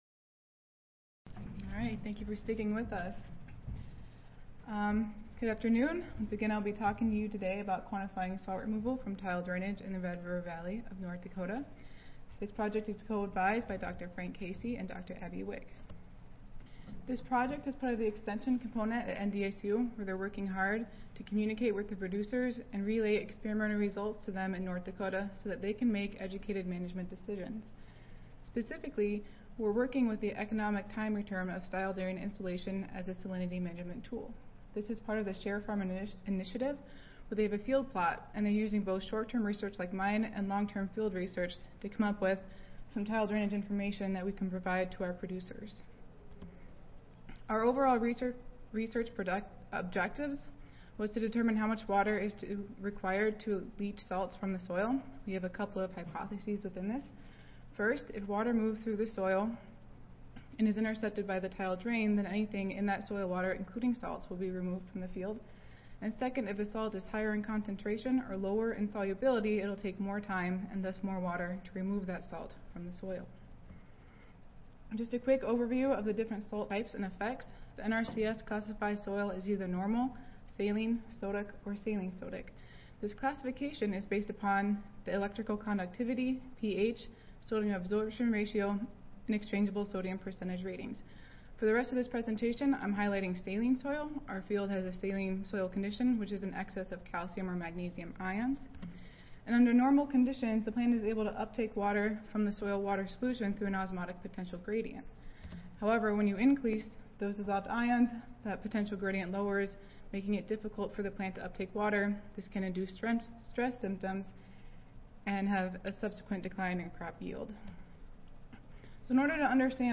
North Dakota State University Audio File Recorded Presentation